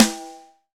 Index of /90_sSampleCDs/Roland L-CDX-01/SNR_Rim & Stick/SNR_Rim Modules
SNR RINGER08.wav